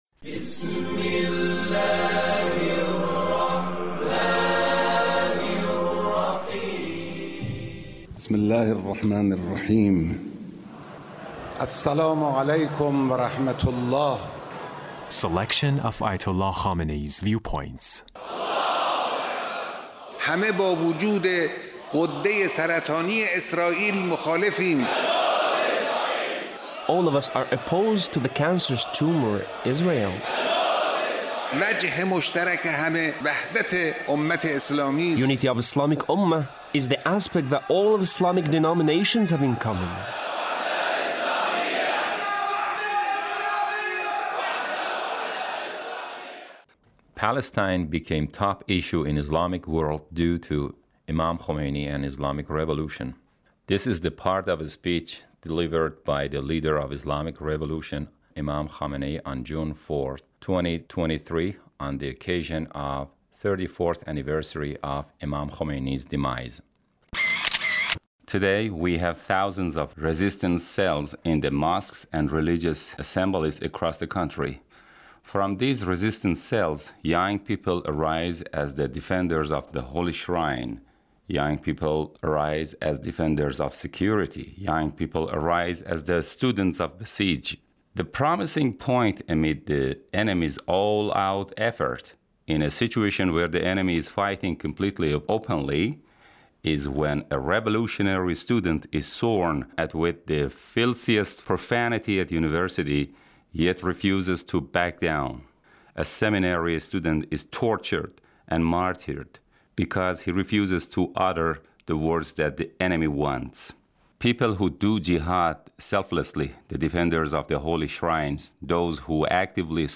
Leader's Speech on the occasion of the 34th ََAnniversary of Imam Khomeini’s Demise. 2025